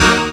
37jt01syn-d#.wav